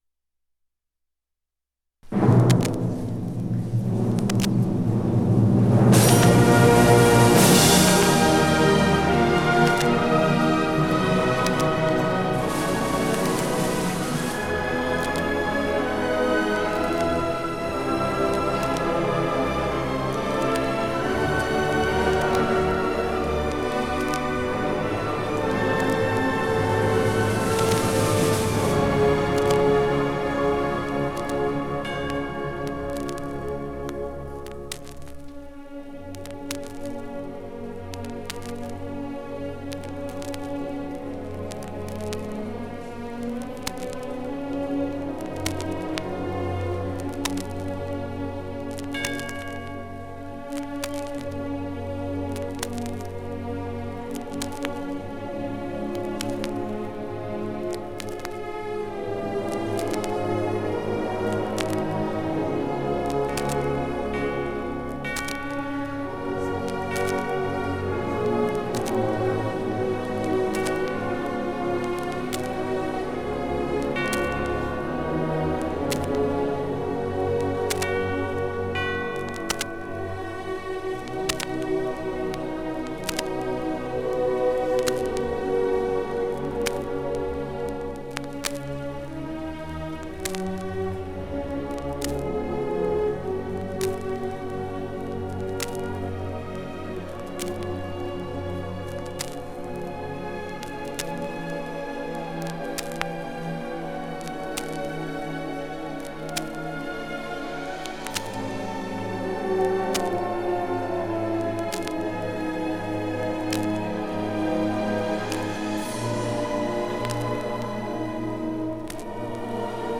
1976 Music in May chorus and orchestra performance recording · Digital Exhibits · heritage
41366f649981e6ea03823f27c9abe301dd2064e1.mp3 Title 1976 Music in May chorus and orchestra performance recording Description An audio recording of the 1976 Music in May chorus and orchestra performance at Pacific University. Music in May is an annual festival that has been held at Pacific University since 1948. It brings outstanding high school music students together on the university campus for several days of lessons and events, culminating in the final concert that this recording preserves.